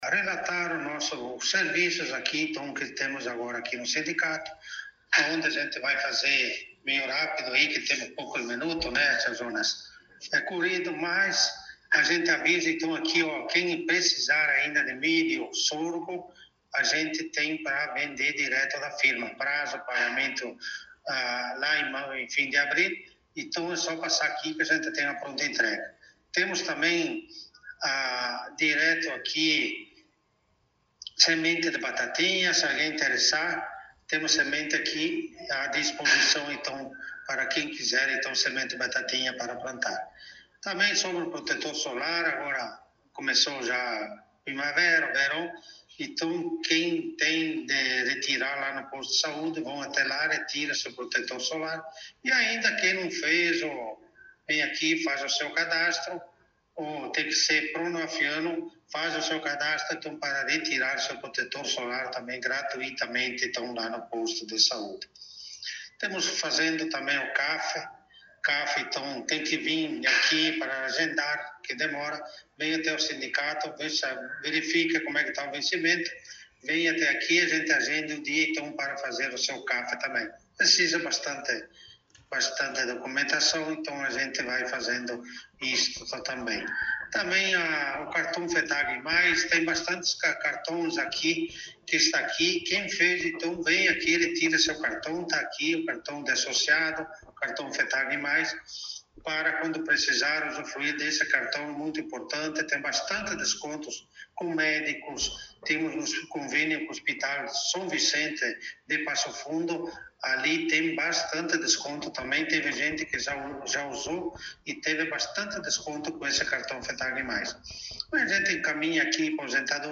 Presidente do STR concedeu entrevista